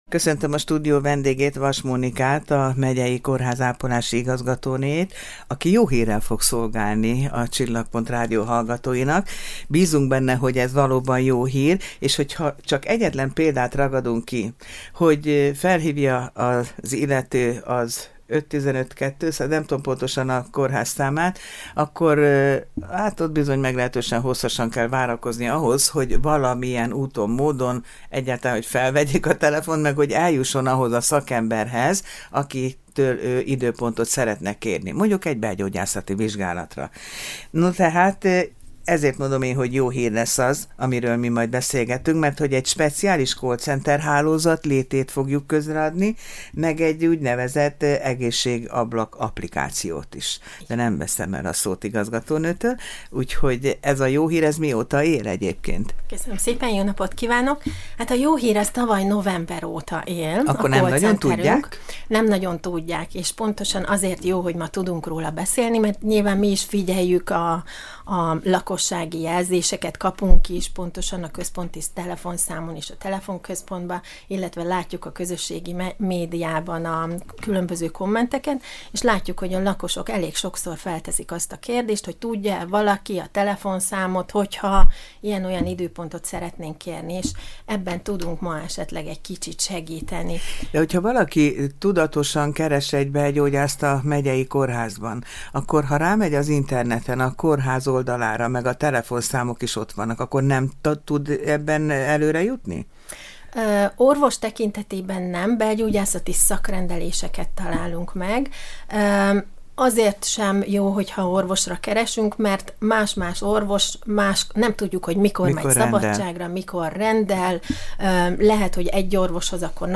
Ugrás a tartalomra Egészség- és sportrádió Miskolc - FM 103 Navigáció átkapcsolása A rádió Munkatársaink Műsoraink Podcastok Kapcsolat Kérjen időpontot szakorvoshoz a miskolci 515-399-es számon!